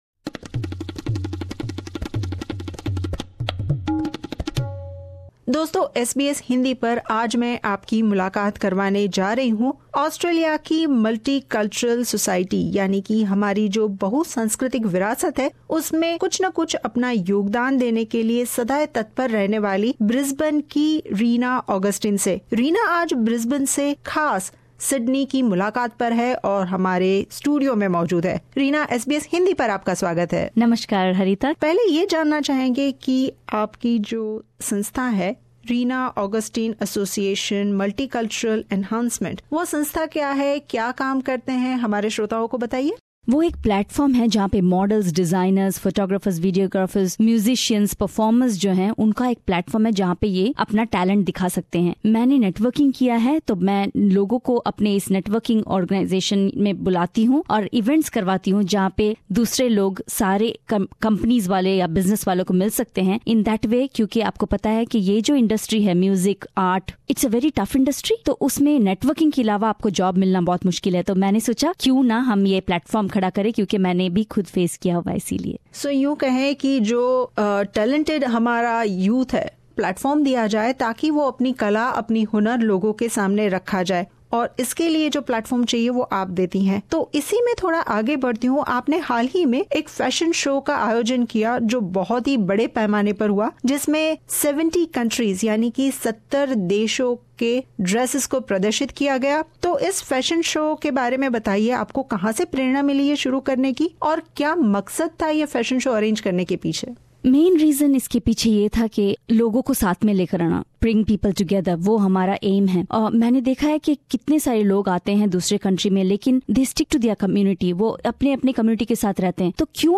विशेष मुलाकात